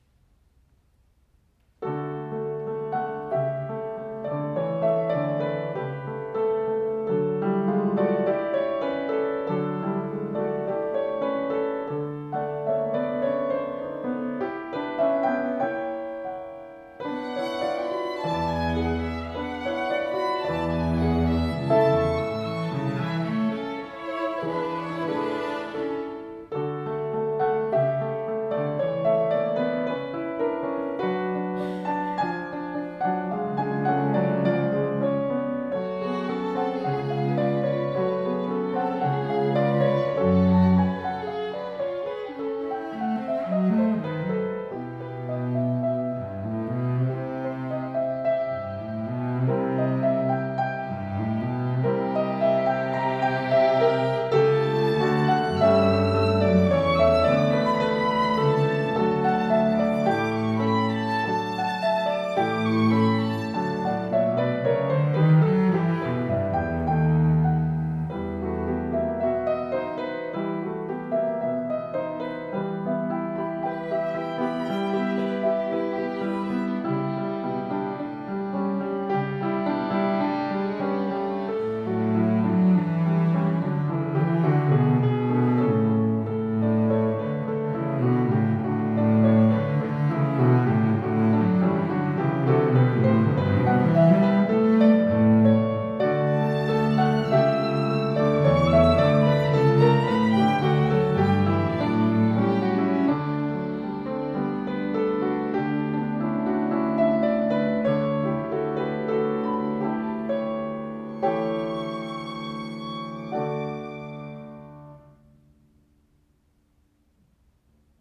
Violine
Violoncello
Klavier
Das Klaviertrio d-Moll von Fanny Hensel-Mendelssohn und die „Pavane pour une infante défunte“ von Maurice Ravel runden das Programm ab und setzen nicht minder reizvolle musikalische Akzente.